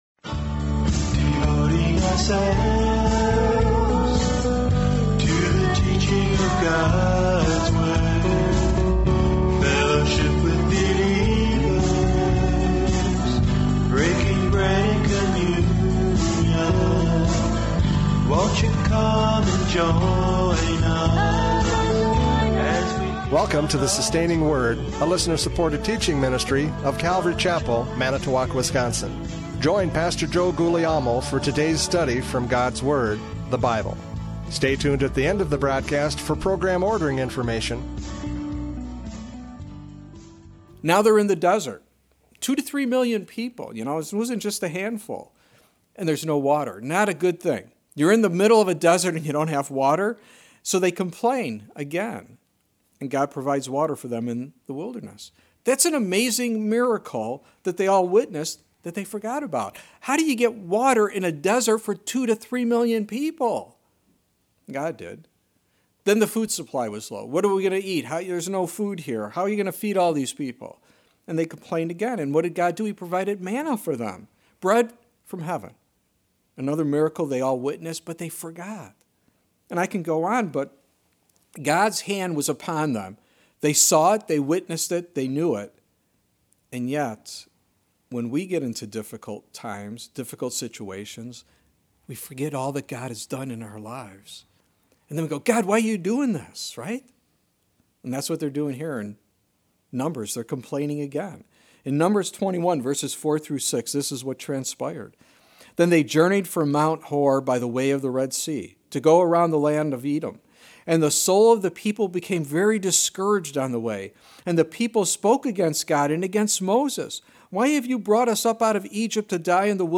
John 3:13-21 Service Type: Radio Programs « John 3:13-21 God’s Love For Us!